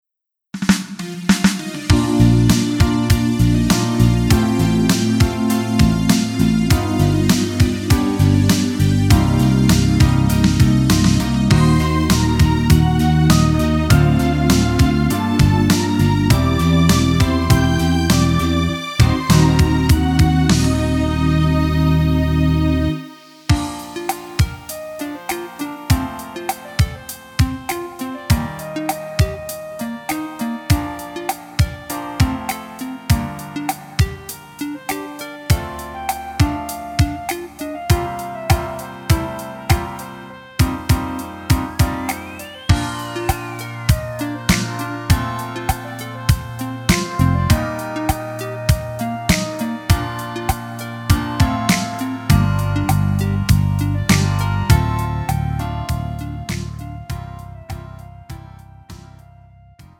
음정 -1키
장르 구분 Pro MR